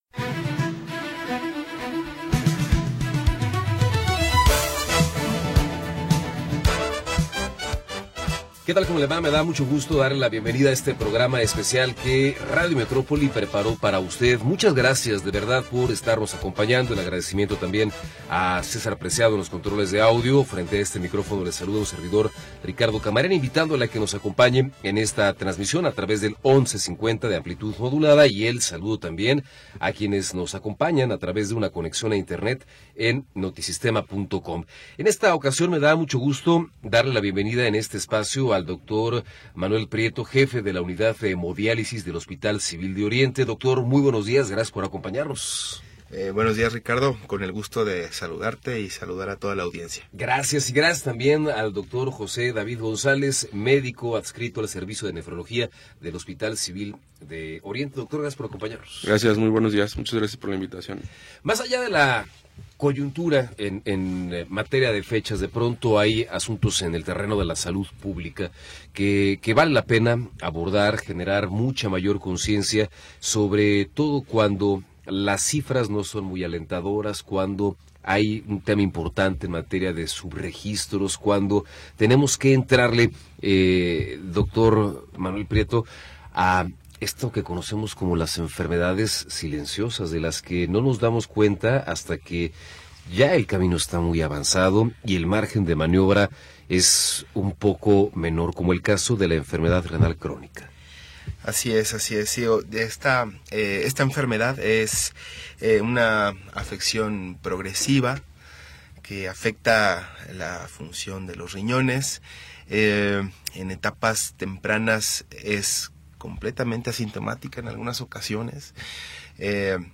Noticias y entrevistas sobre sucesos del momento
Primera hora del programa transmitido el 13 de Diciembre de 2025.